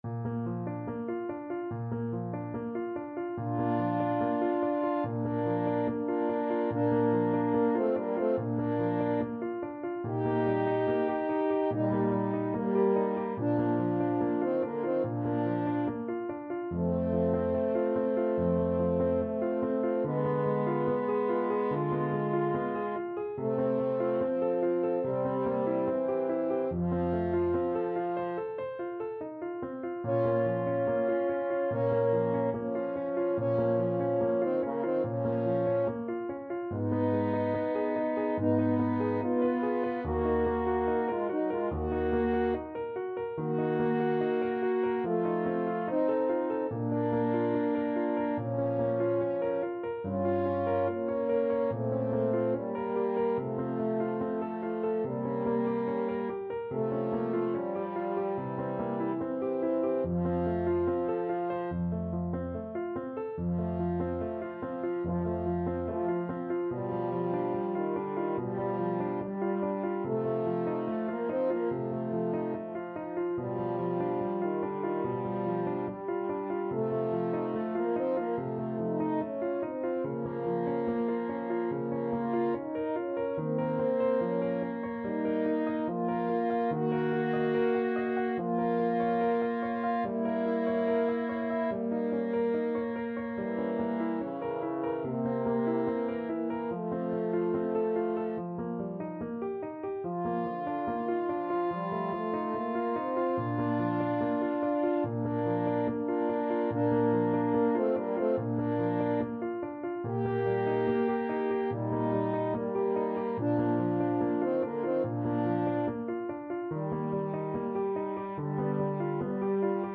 Andante =72
4/4 (View more 4/4 Music)
Classical (View more Classical French Horn Duet Music)